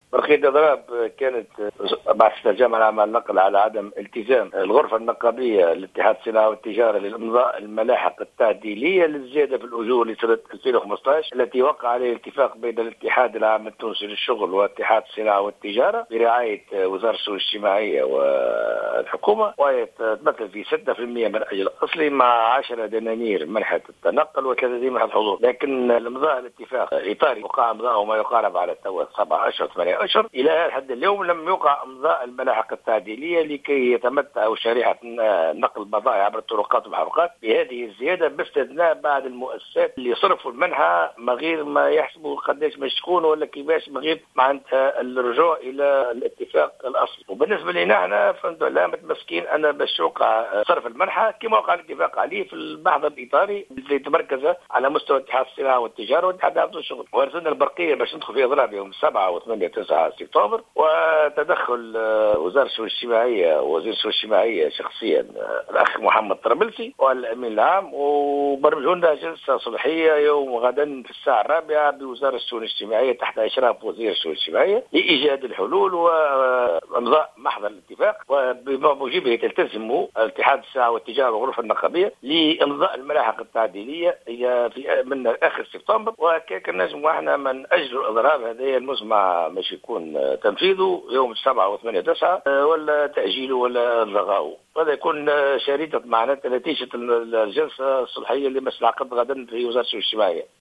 في تصريح لـ "الجوهرة أف أم"